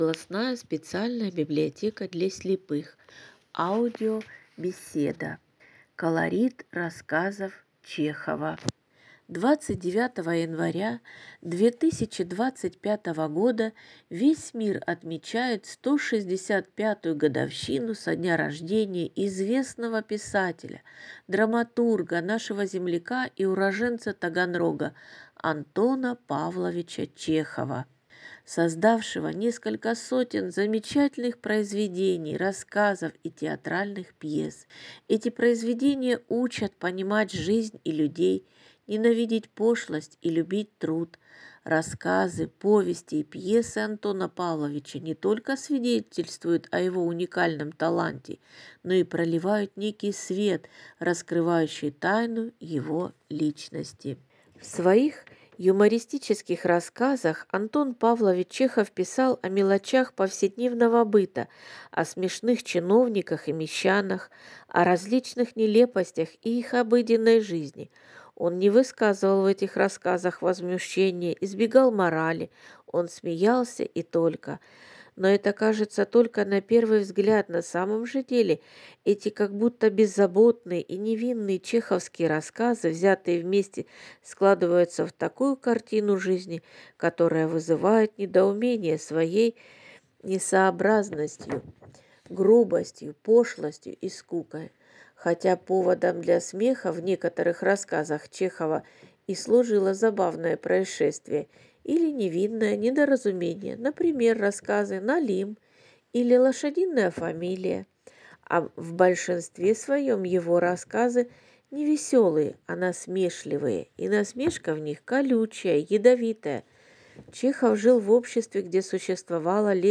Ростовский пункт выдачи библиотеки для слепых представляет аудиобеседу «